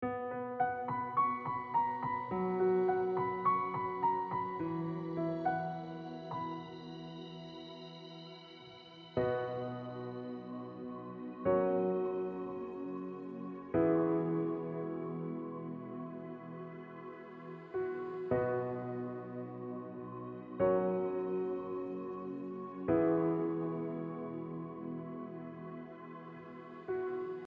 Im Moment teste ich noch viele Demos. gekauft habe ich mir jetzt mal Valhalla Shimmer, das ist einfach krass auf Pads und Drones.